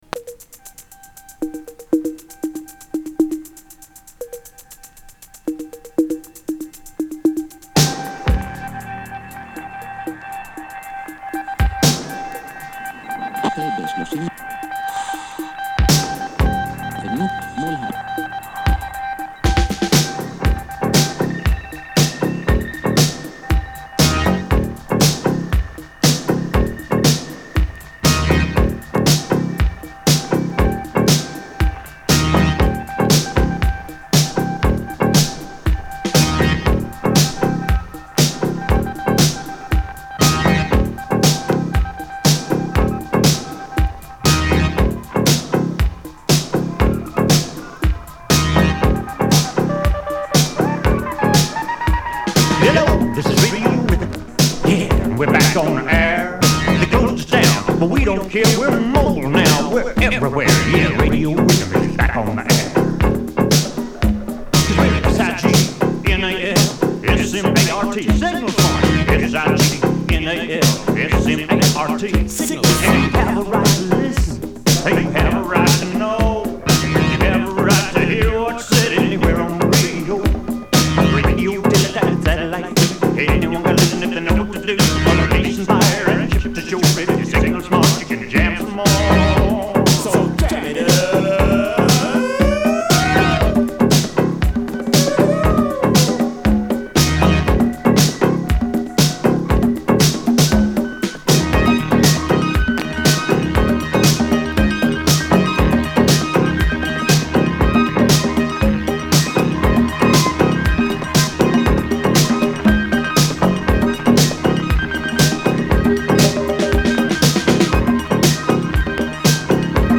Issu d'un vinyle le son est peut-être moyen.